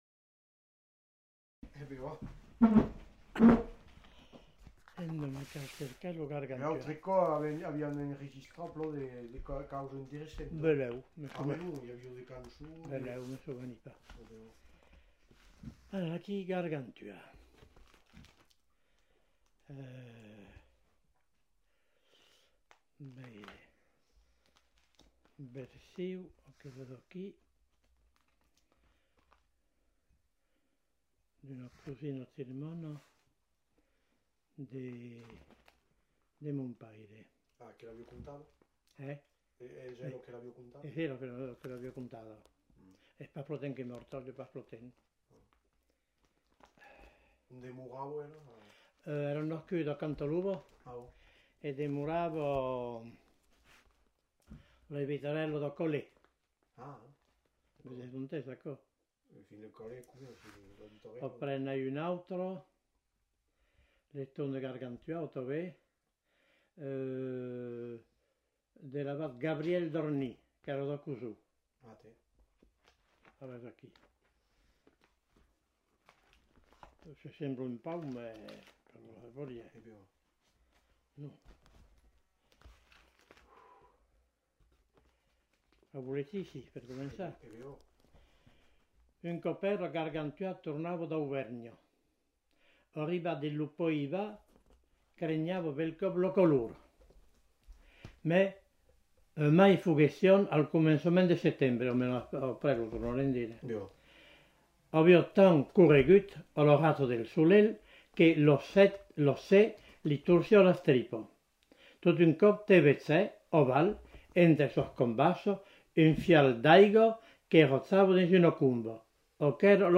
Genre : conte-légende-récit
Type de voix : voix d'homme Production du son : lu Classification : récit légendaire